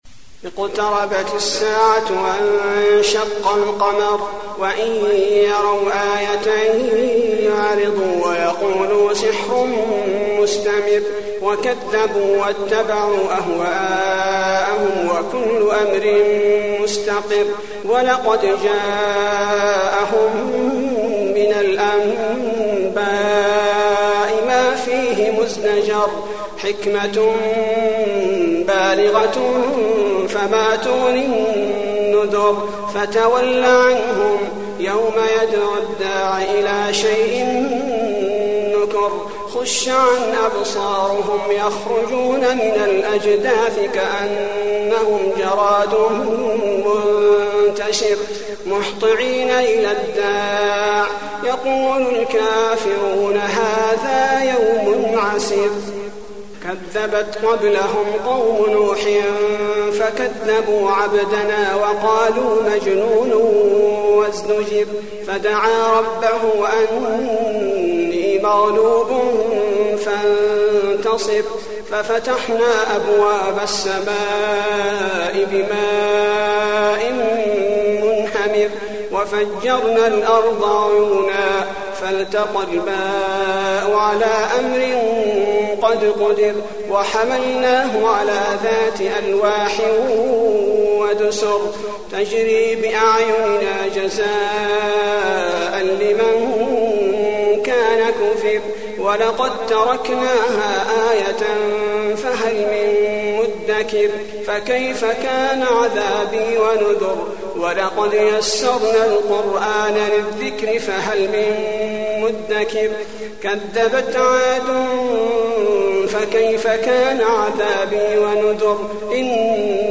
المكان: المسجد النبوي القمر